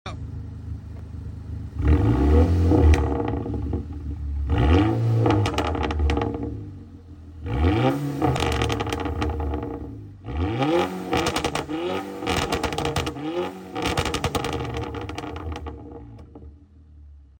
Video does not come close to how it sounds in person • MK6 GTI IE CRACKLES Catless downpipe with aftermarket resonator and muffler delete